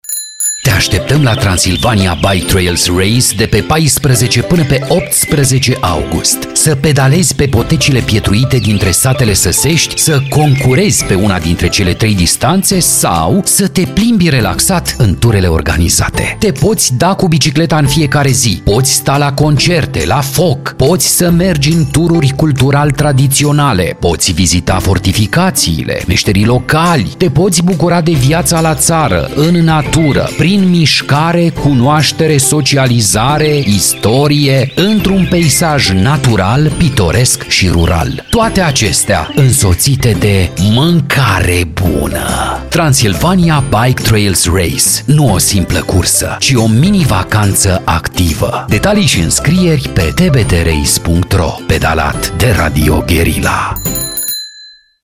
Spot Radio Guerrilla – TBT Race 2024